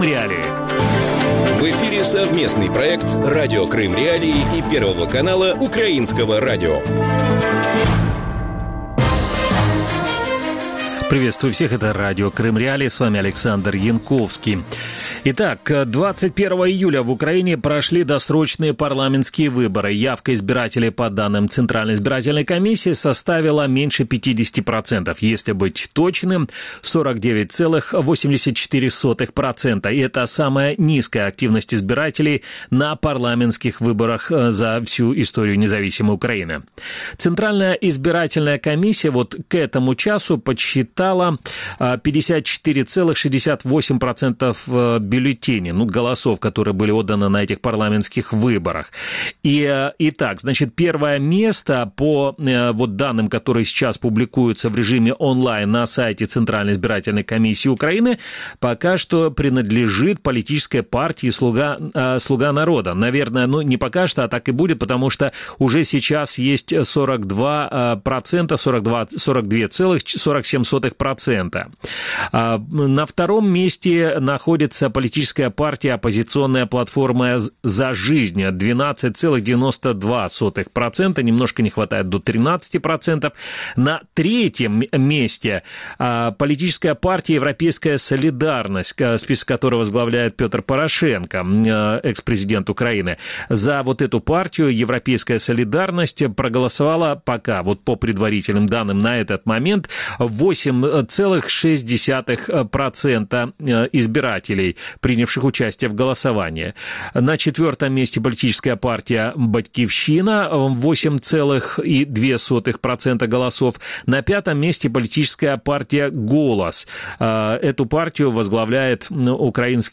Кто из крымчан будет депутатом Верховной Рады 9 созыва? Какие законопроекты, касающиеся Крыма, уже есть в их портфелях? И как голосовали крымчане на этих парламентских выборах? Гости эфира: